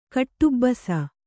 ♪ kaṭṭubbasa